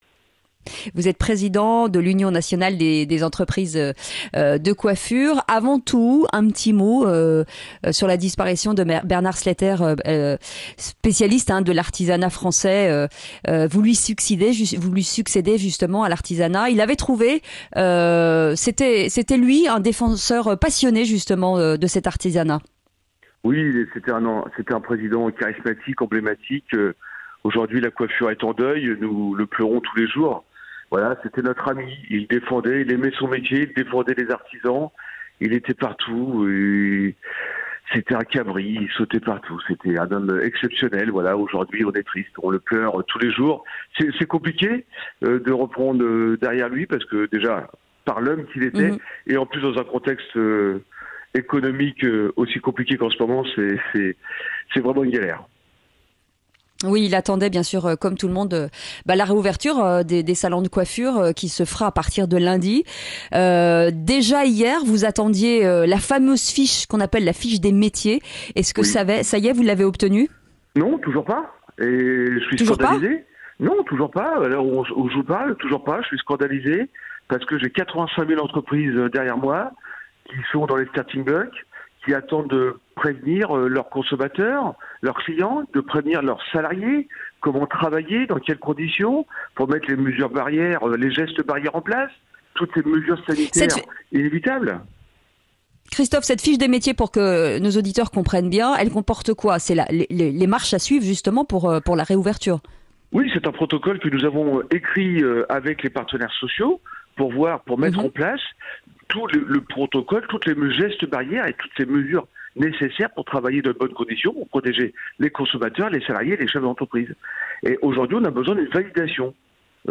Sud Radio à votre service dans Le Grand Matin Sud Radio à 6h50 avec FIDUCIAL.